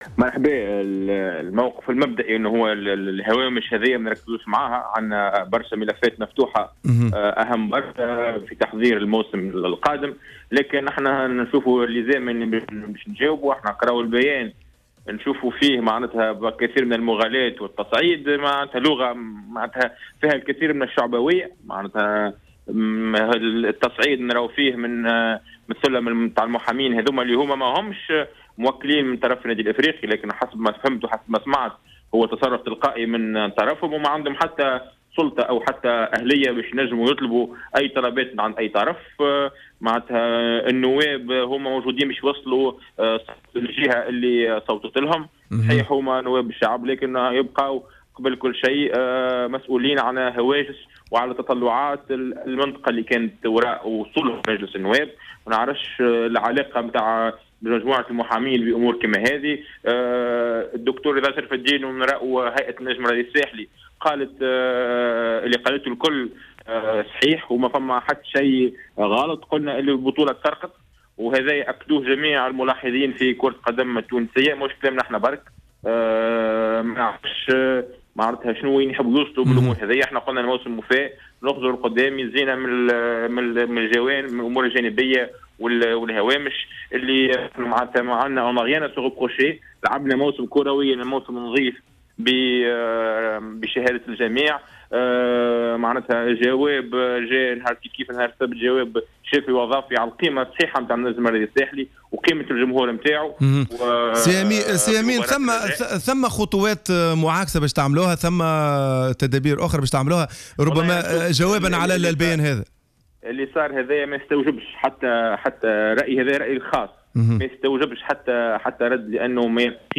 على أمواج جوهرة أف أم